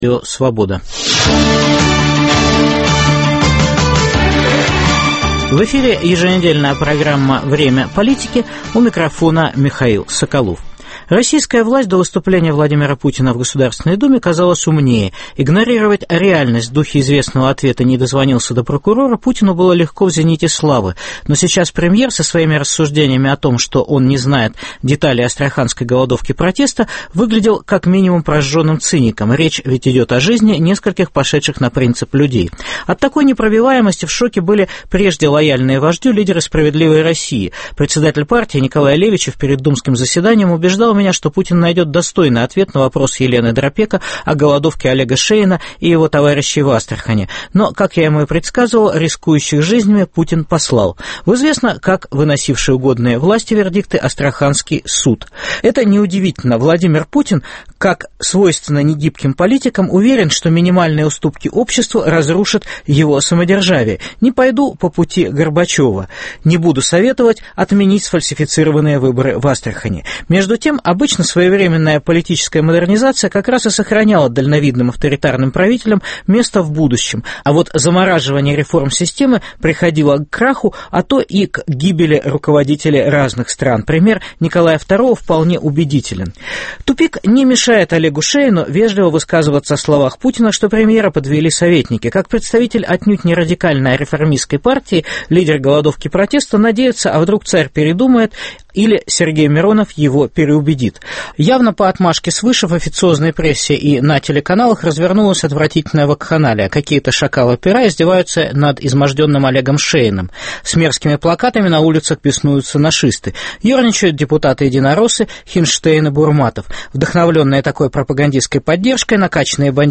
Астрахань: пролог к переменам в России. Интервью с Олегом Шеиным. Победит ли гражданский кандидат на выборах мэра Омска?